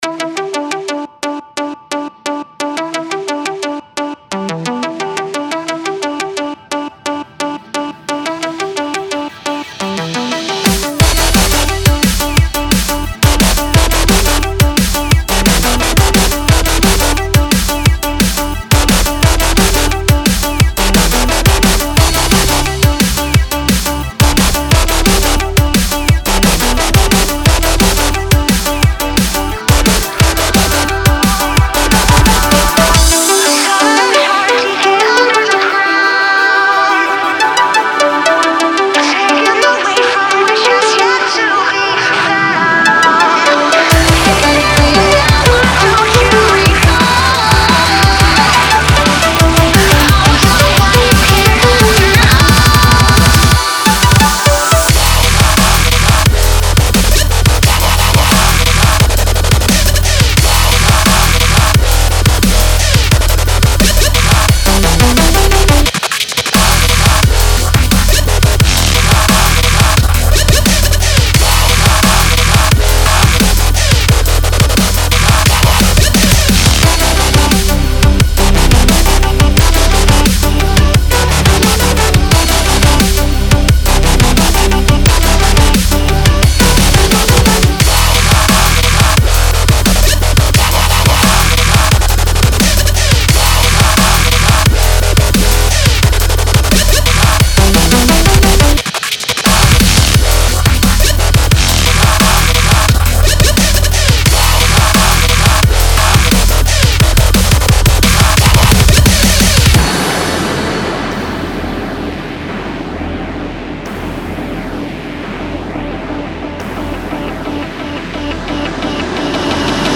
DUB STEP--> [6]